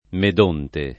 [ med 1 nte ]